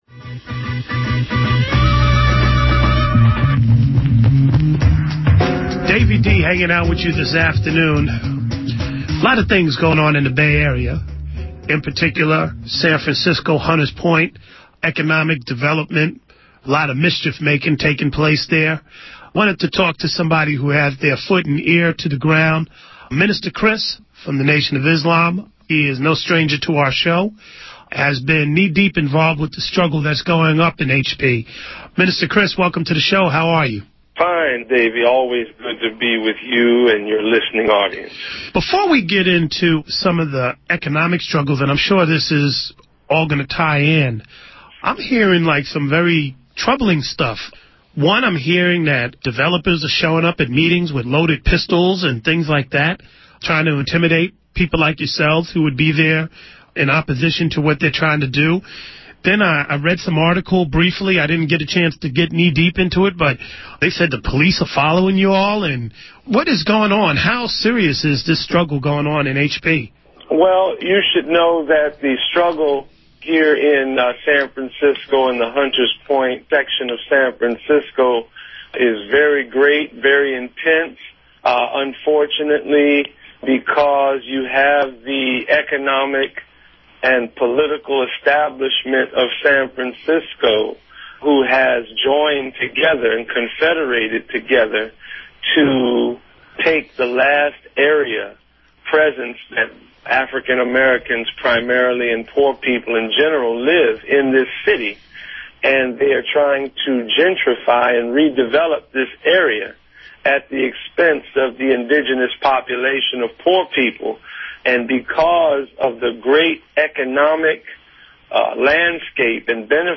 hunters-point-slam_hard-knock-radio.mp3